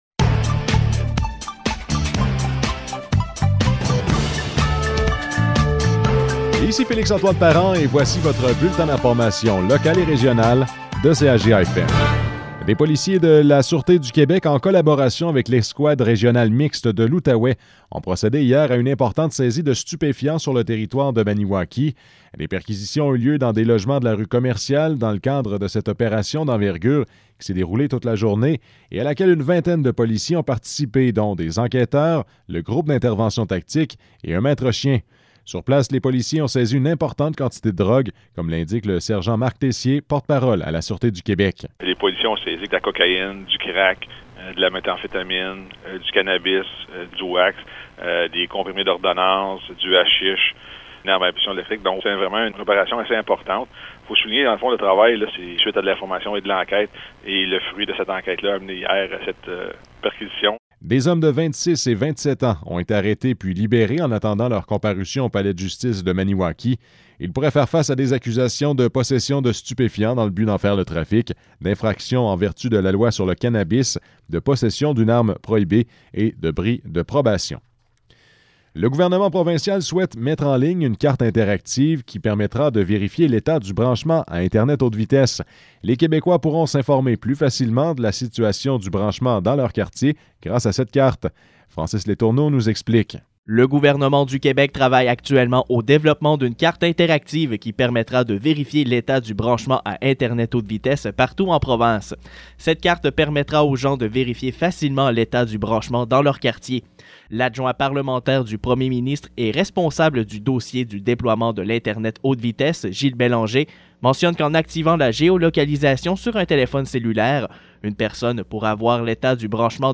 Nouvelles locales - 10 mars 2022 - 12 h